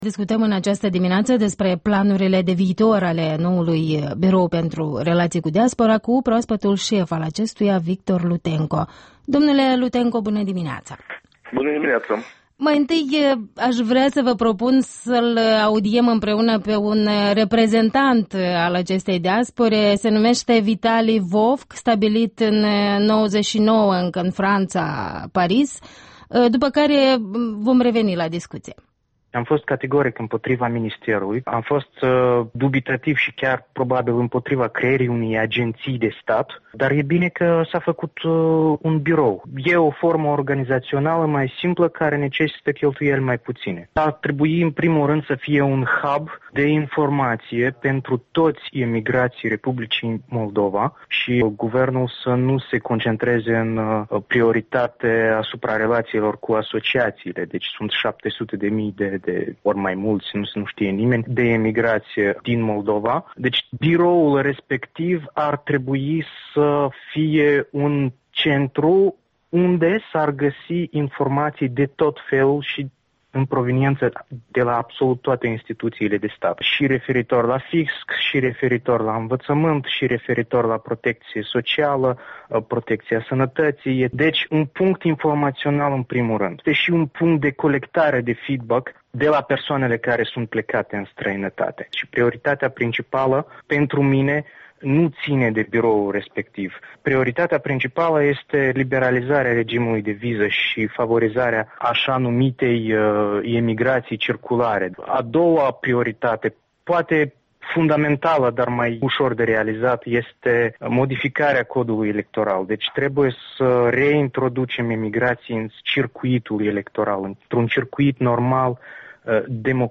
Despre Diaspora moldoveană și relațiile cu ea - o discuție